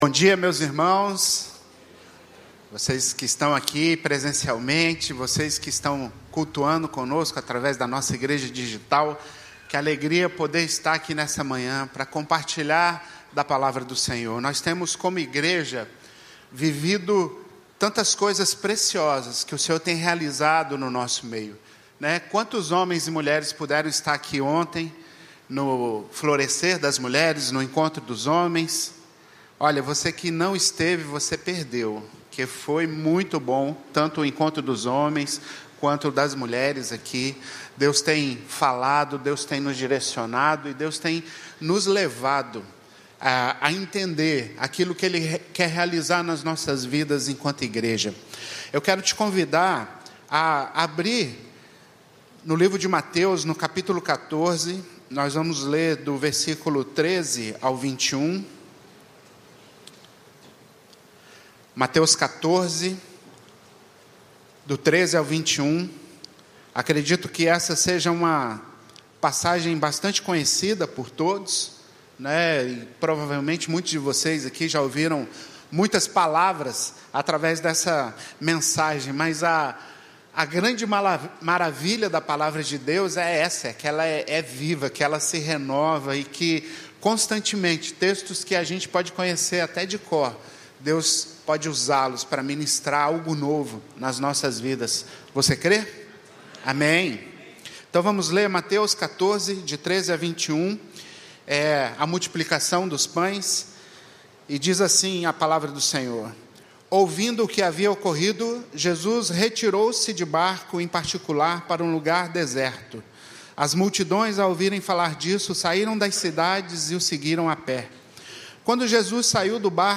Igreja Batista do Recreio